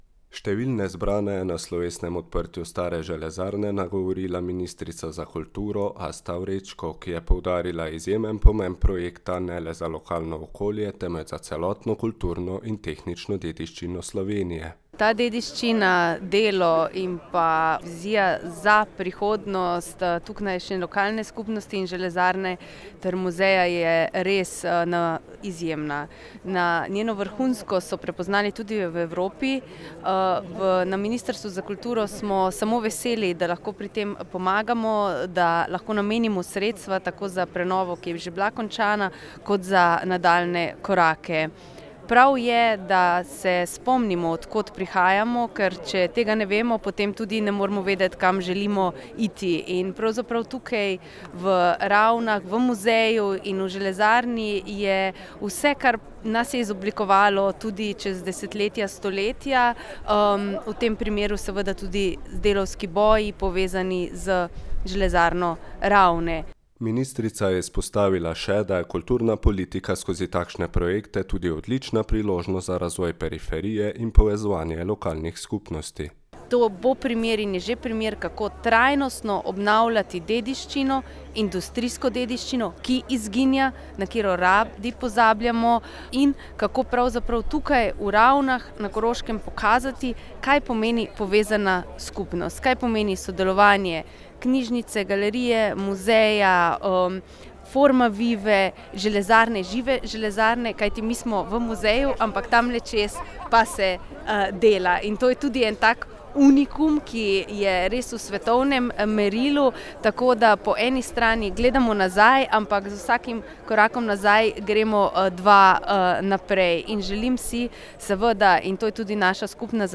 Slavnostno odprtje muzejskega območja
Številne zbrane je na slovesnem odprtju Stare železarne nagovorila ministrica za kulturo Asta Vrečko, ki je poudarila izjemen pomen projekta ne le za lokano okolje, temveč za celotno kulturno in tehnično dediščino Slovenije.